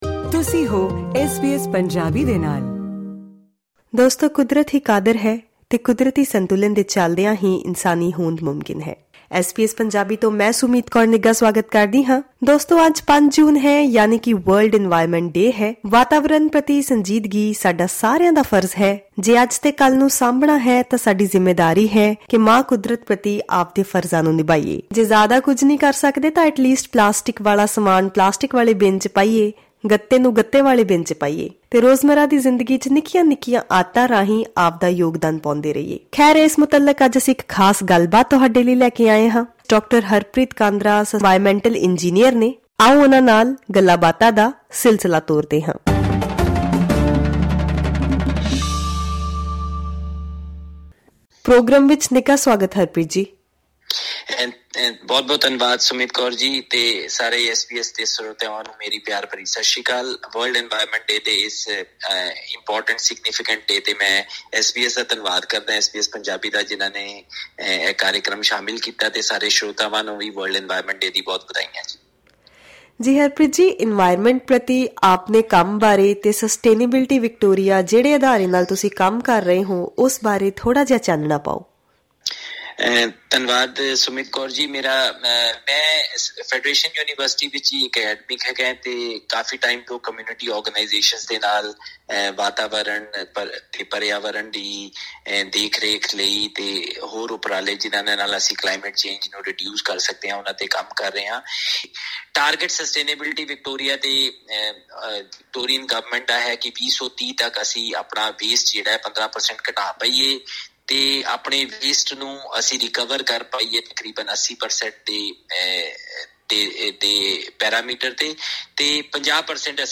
Full interview: